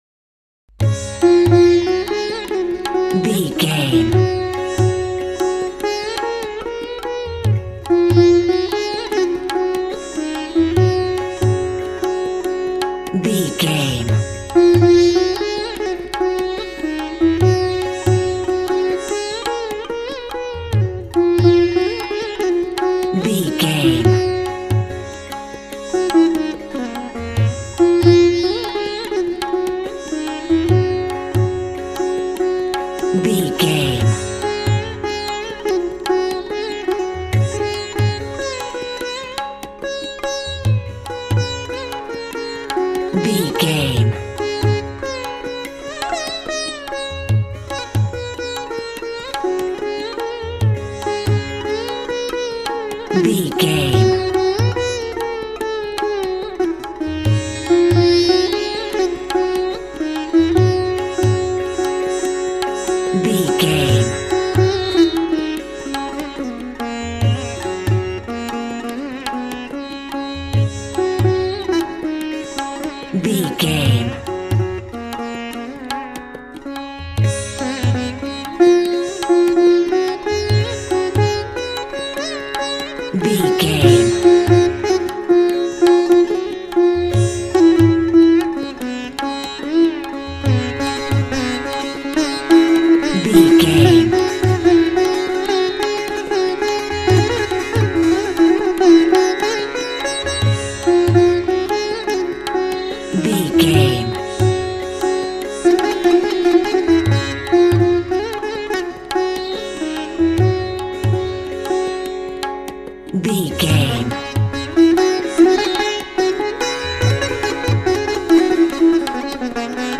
Mixolydian
D♭
World Music
percussion